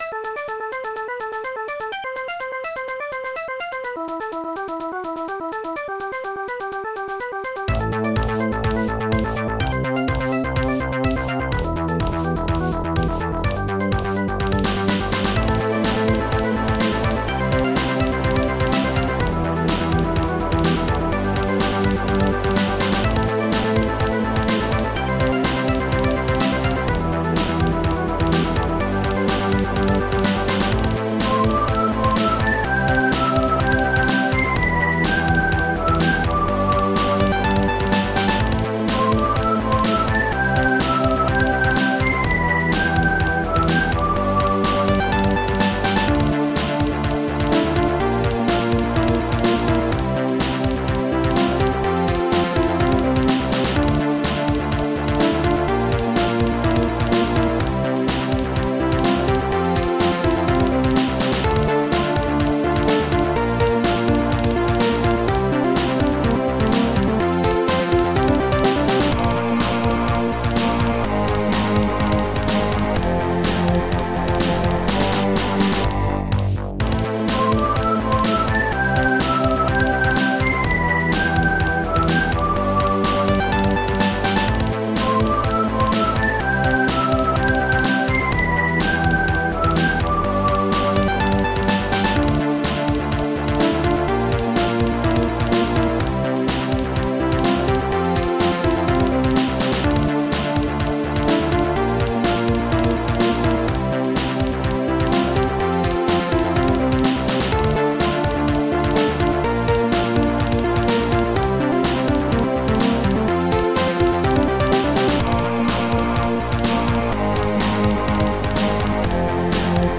Protracker Module
ringpiano